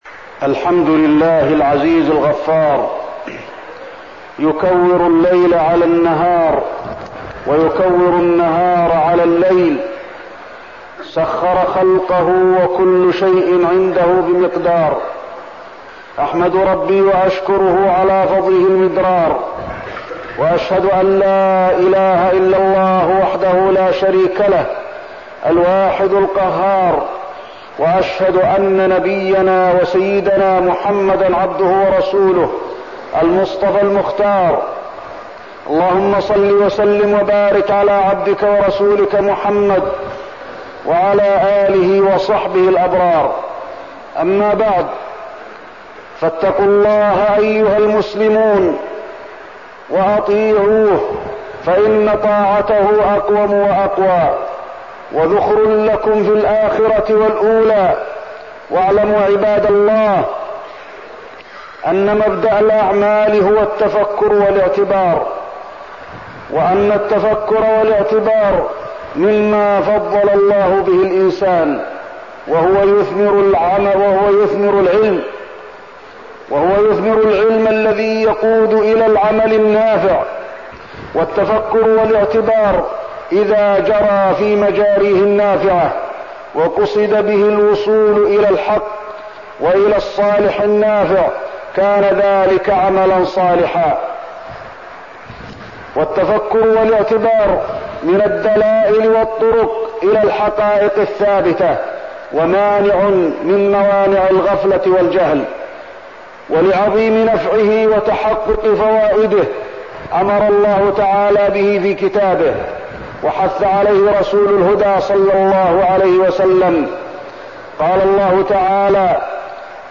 تاريخ النشر ٢٨ جمادى الأولى ١٤١٤ هـ المكان: المسجد النبوي الشيخ: فضيلة الشيخ د. علي بن عبدالرحمن الحذيفي فضيلة الشيخ د. علي بن عبدالرحمن الحذيفي التفكر والإعتبار